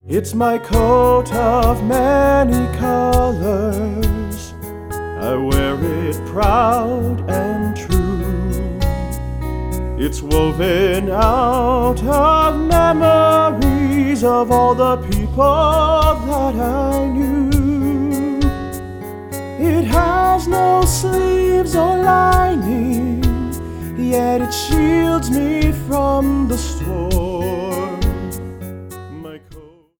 Inspirational Song Lyrics and Sound Clip